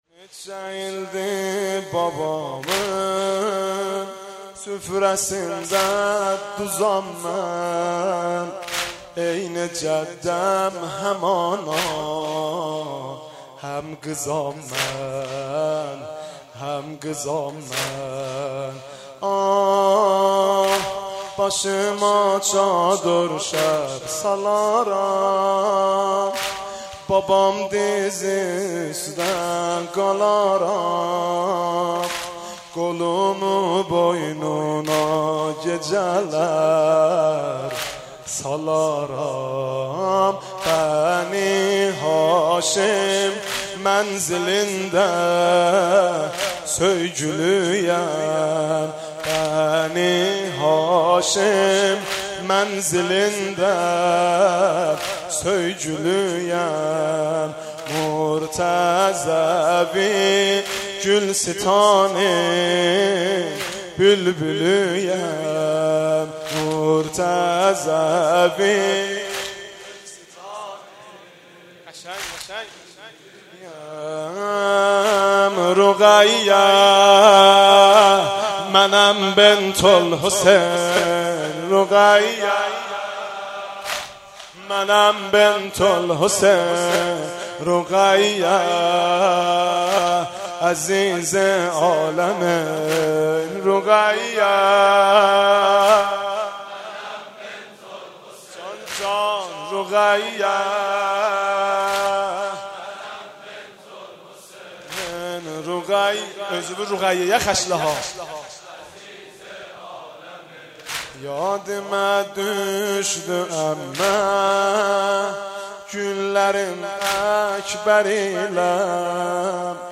خیمه گاه - حاج مهدی رسولی - بخش چهارم - واحدترکی (نچه ایلدی بابامین سفره سینده دوزام من)
شب سوم محرم1394